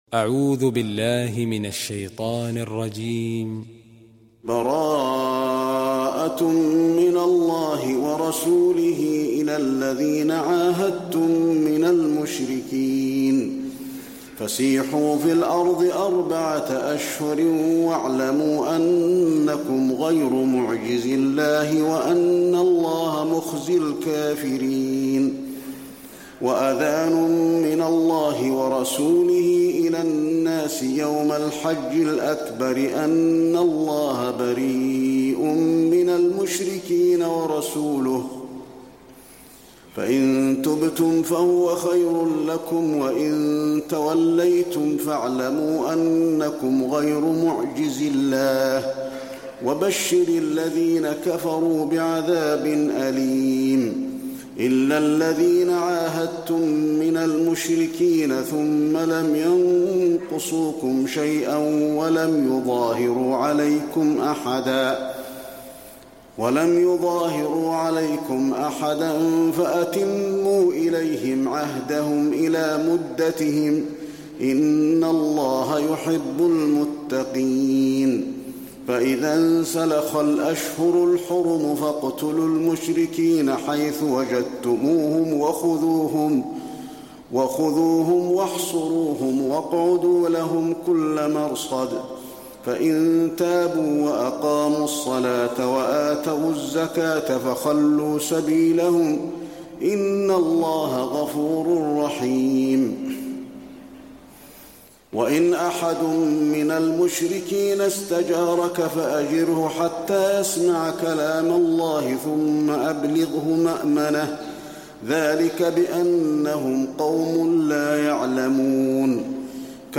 المكان: المسجد النبوي التوبة